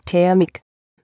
Das Sprachfile habe ich auch mit der AT&T Seite neu erstellt, dort einfach ein bisschen mit "ä" und "h" rumspielen, bis die Aussprache passt.